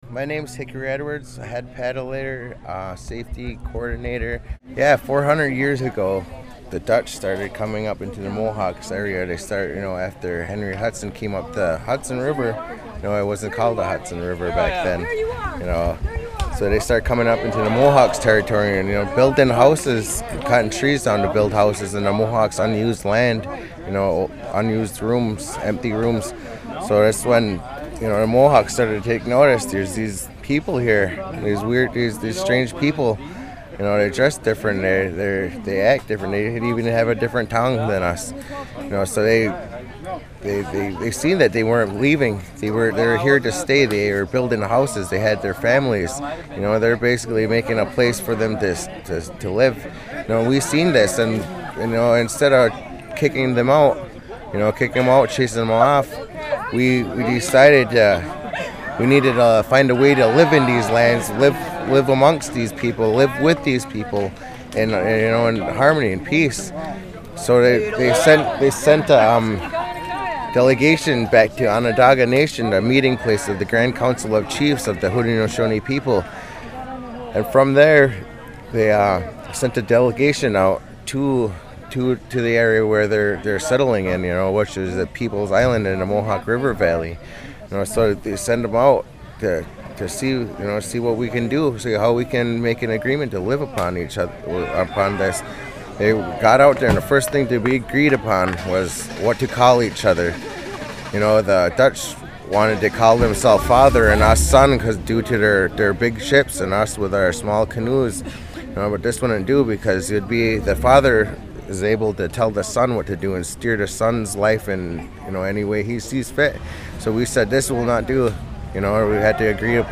He describes the history of the 2 Row treaty. There was a pickup whiffle ball game going on in the background.